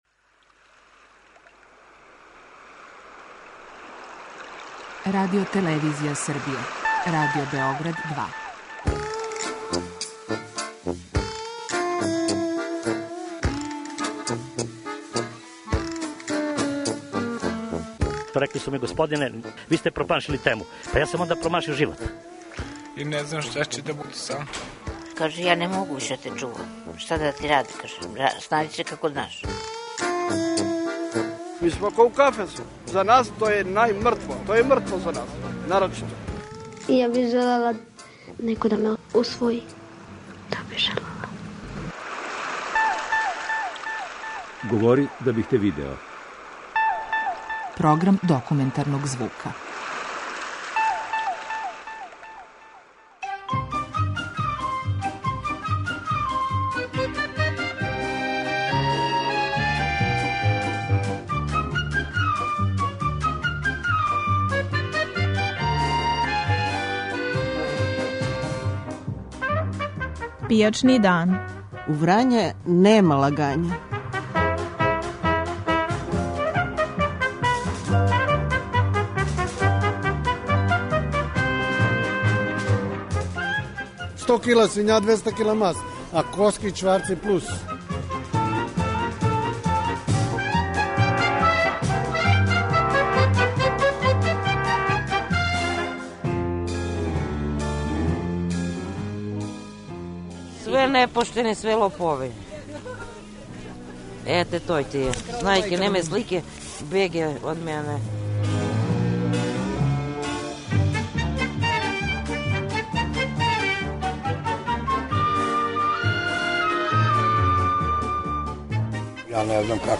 Документарни програм
Слушаћете репортажу под називом У Врање нема лагање.